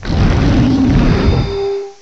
cry_not_stakataka.aif